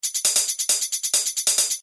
描述：一些从切碎的beatbox会话中制作的break loops。 breakbeat beatbox beat box打击乐鼓
标签： 135 bpm Breakbeat Loops Percussion Loops 306.42 KB wav Key : Unknown
声道立体声